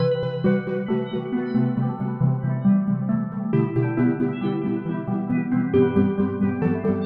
描述：F大调
标签： 136 bpm Hip Hop Loops Bells Loops 1.19 MB wav Key : F
声道立体声